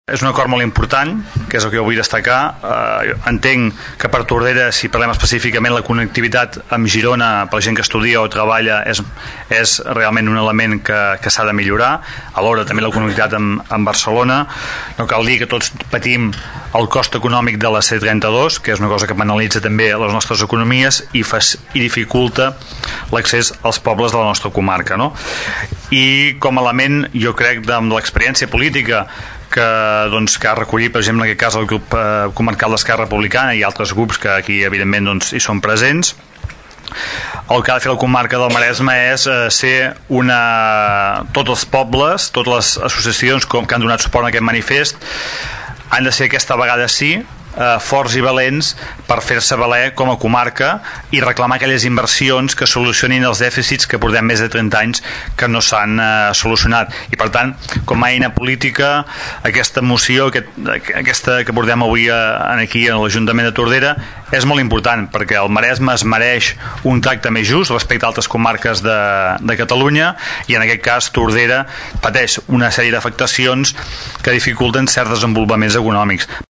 Xavier Pla, regidor d’ERC+Gent de Tordera, anima les entitats a seguir pressionant perquè la Generalitat dugui a terme allò al qual es va comprometre. Remarca la importància d’aquest acord i creu que el Maresme s’ha de fer valer com a comarca.